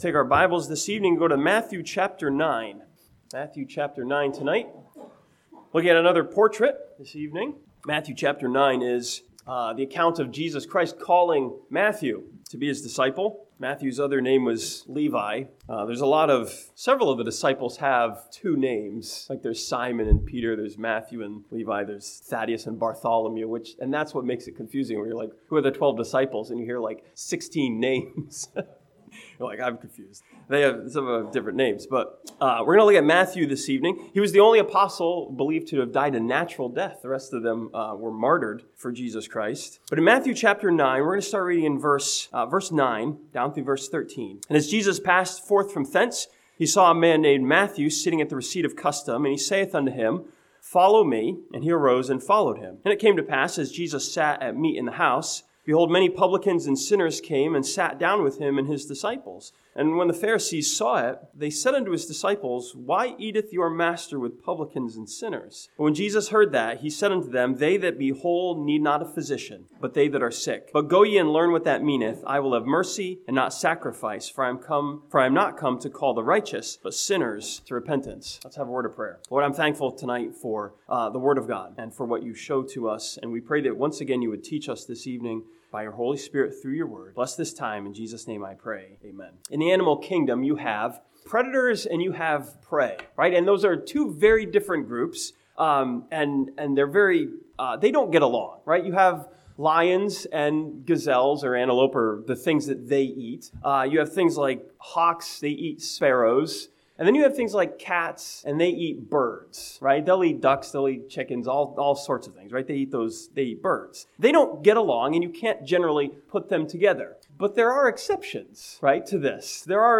This sermon from Matthew chapter 9 studies the disciple Matthew as a portrait of a friend of Jesus Christ.